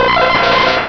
exeggcute.wav